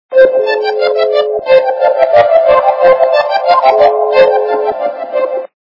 » Звуки » звуки для СМС » Тон - Для СМС 18
При прослушивании Тон - Для СМС 18 качество понижено и присутствуют гудки.